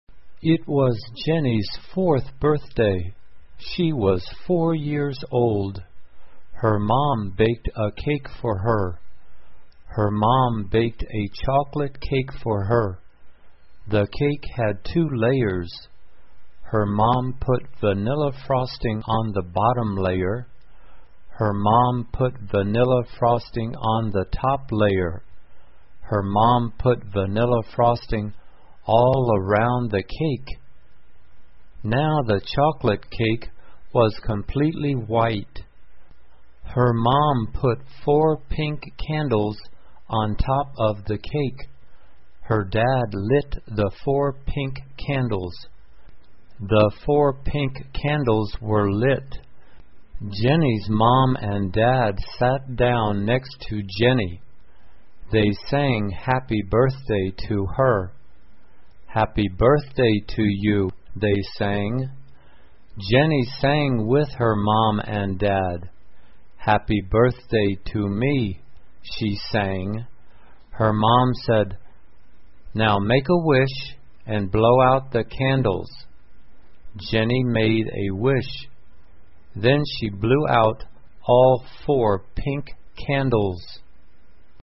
慢速英语短文听力 生日蛋糕 听力文件下载—在线英语听力室